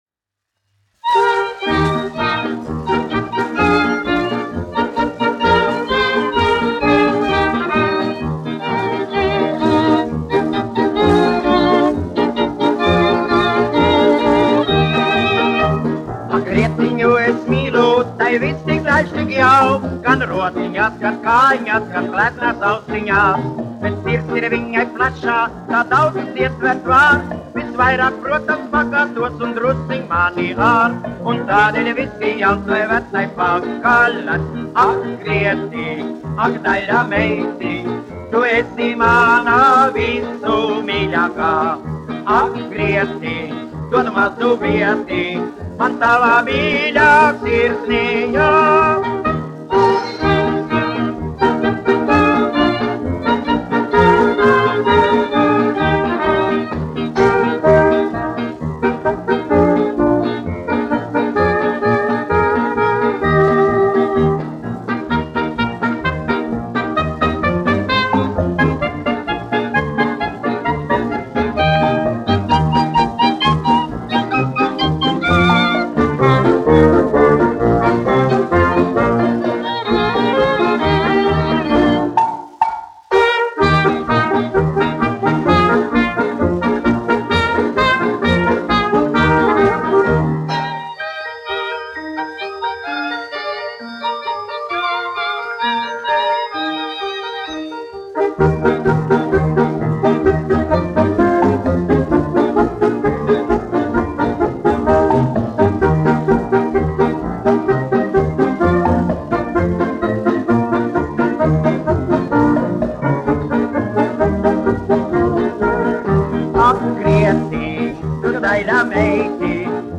1 skpl. : analogs, 78 apgr/min, mono ; 25 cm
Populārā mūzika
Fokstroti
Latvijas vēsturiskie šellaka skaņuplašu ieraksti (Kolekcija)